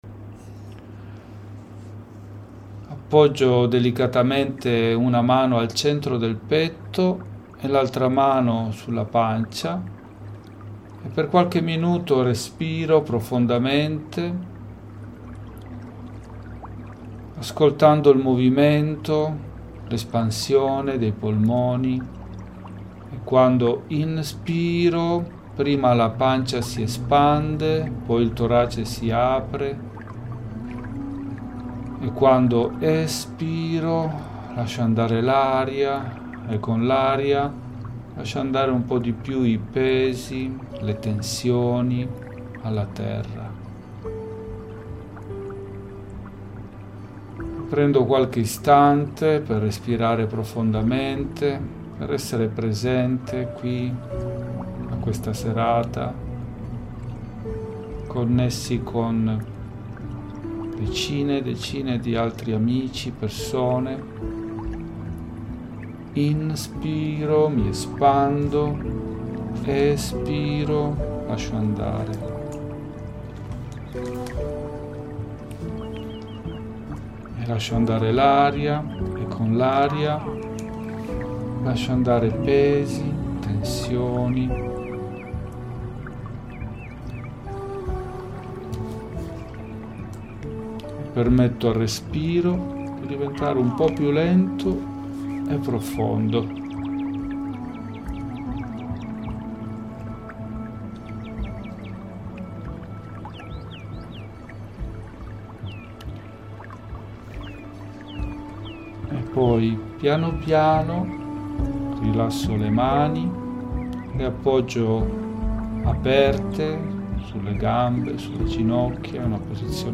MEDITAZIONE GUIDATA e CERCHIO REIKI
Qui trovi la registrazione audio della meditazione guidata e del Cerchio Reiki, così puoi riascoltarla e riprendere lo spazio di centratura ogni volta che ne senti il bisogno. scarica il file audio Buon ascolto e a presto nel cerchio 🌿✨